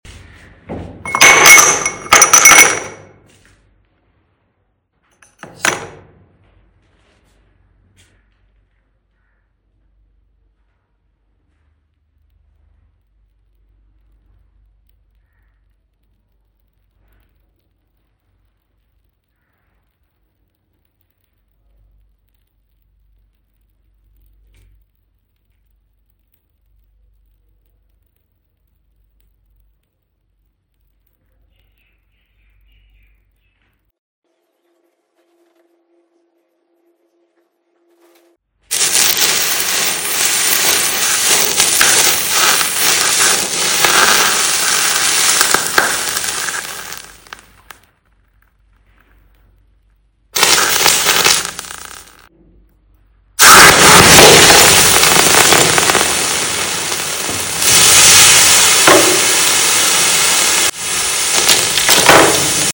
Breaking Science Beaker With 1000°C